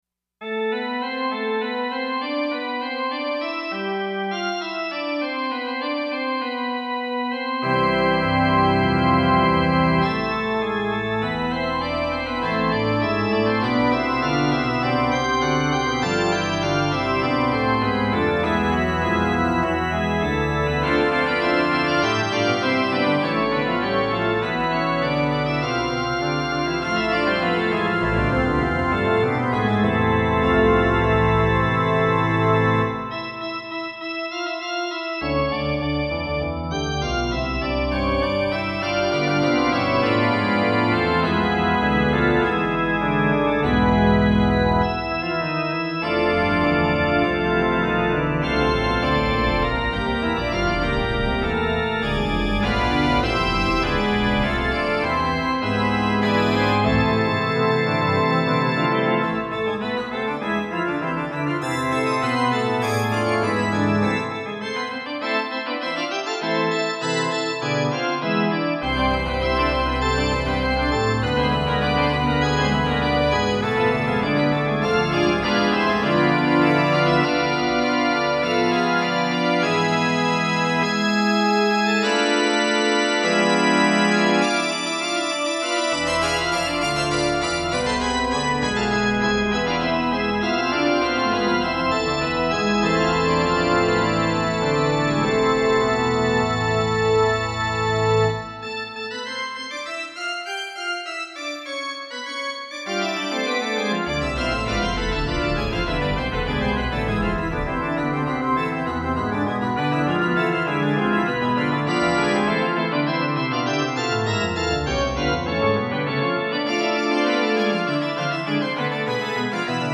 クラシック　 ファイル名